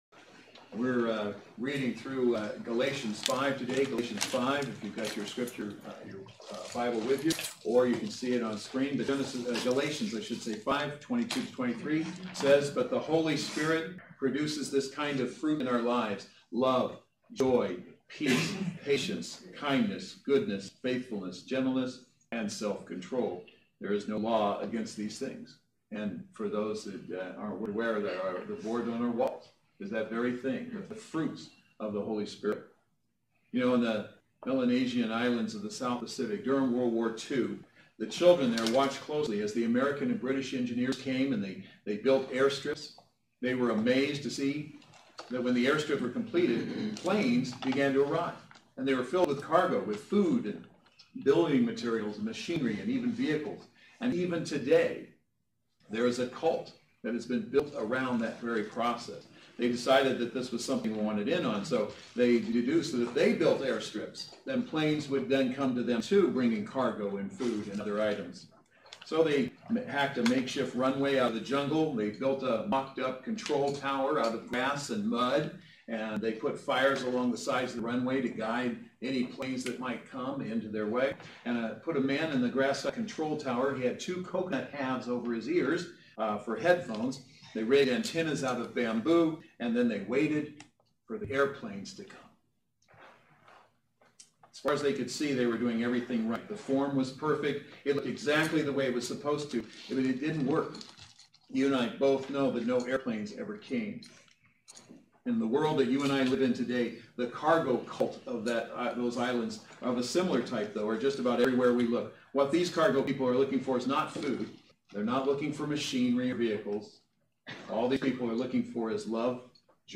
Passage: Galations 5 Service Type: Saturday Worship Service